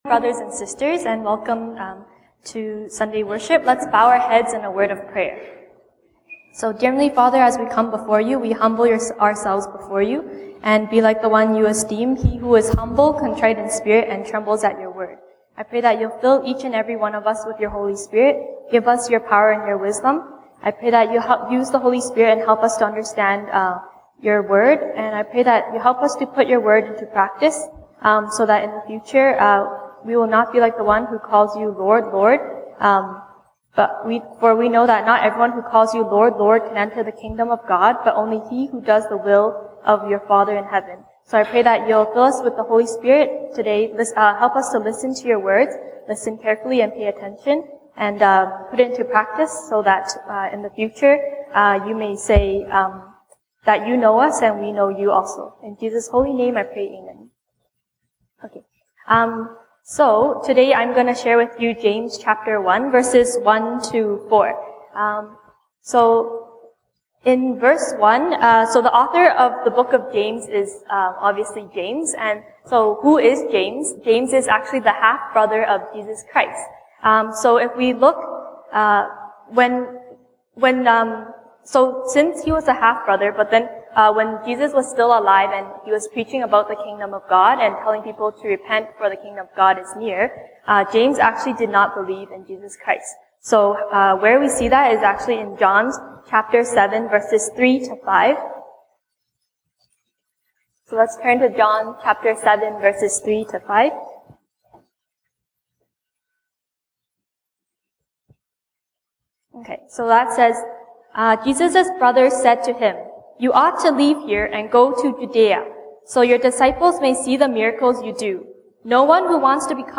Sunday Service English Topics